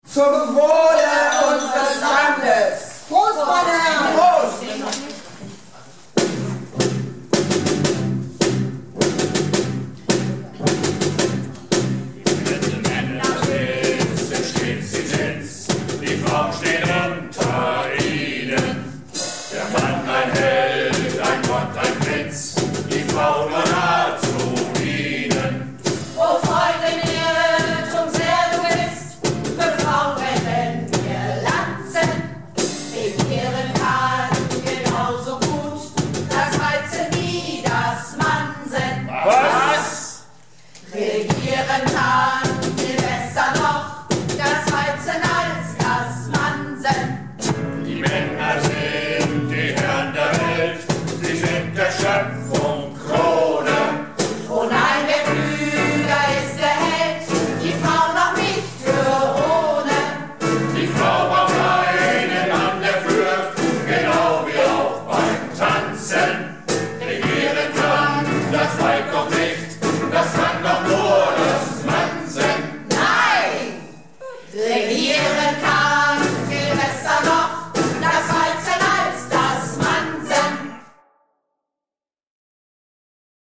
Projektchor "Keine Wahl ist keine Wahl" - Theaterprobe 28.09.19